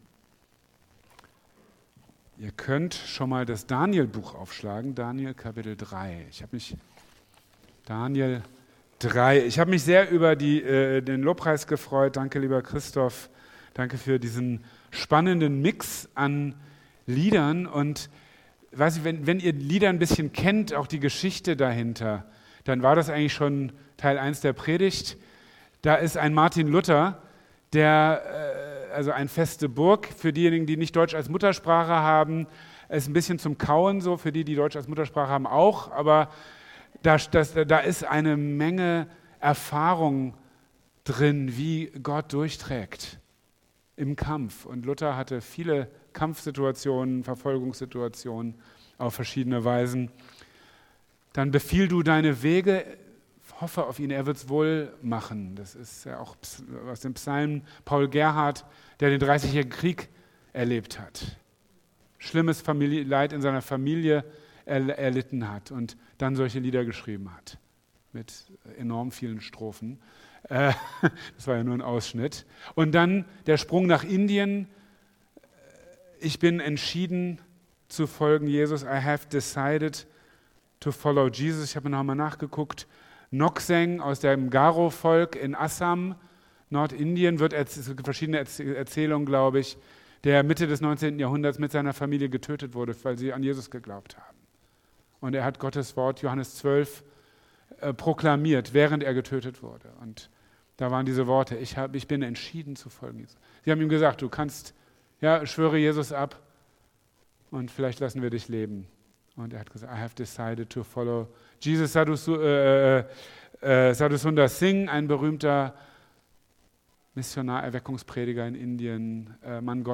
In Daniel 3,1-28 lesen wir von den Freunden Daniels, die aufgrund ihres Bekenntnisses zu dem Gott der Bibel verfolgt werden. Noch erstaunlicher als Gottes Bewahrung ist ihre Entschlossenheit, für ihn alles zu riskieren. In seiner Predigt im Gebetsgottesdienst für verfolgte Christen am 9. November 2025